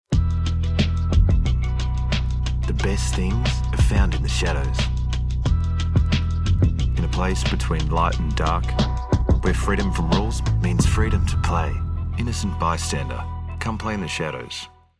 Olá, sou uma dubladora profissional de inglês australiano com uma entrega calorosa, clara e versátil.
Inclui um microfone Rode NT1-A e uma interface de áudio, tudo instalado em uma cabine à prova de som para garantir excelente qualidade de gravação.